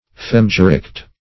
Search Result for " fehmgericht" : The Collaborative International Dictionary of English v.0.48: Fehm \Fehm\, n., Fehmgericht \Fehm"ge*richt`\, n. Same as Vehm , Vehmgericht .